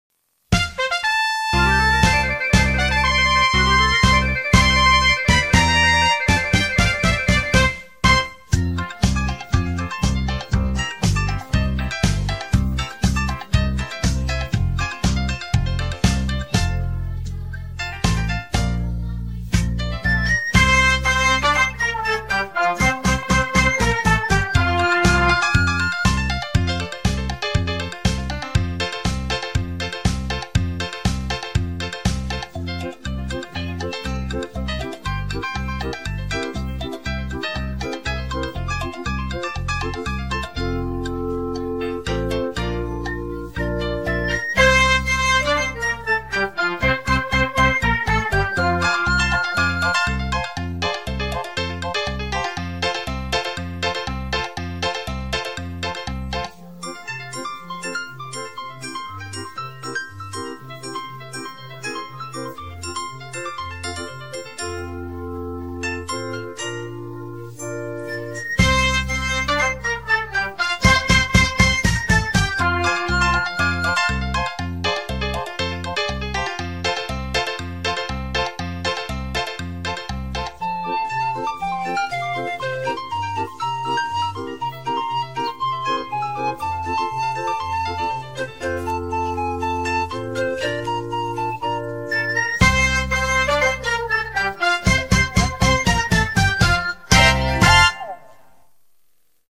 Lyrics are on the screen, and it’s Instrumental!